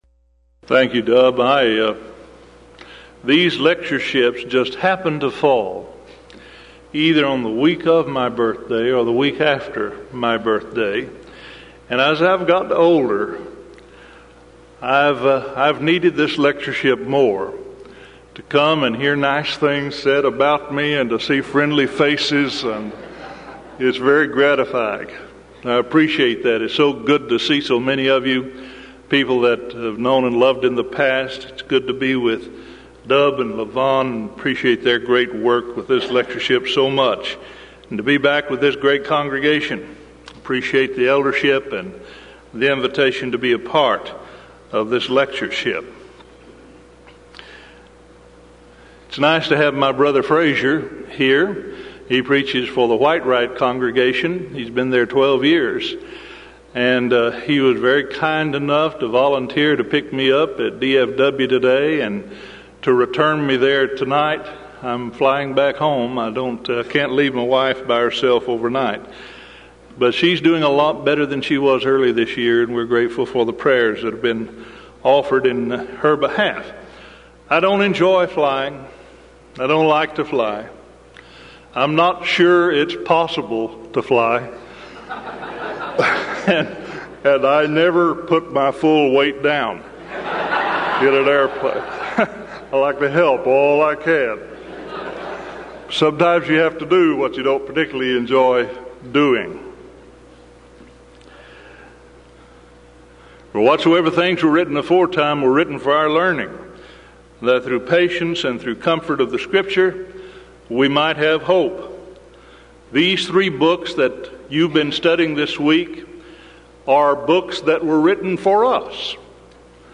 Series: Denton Lectures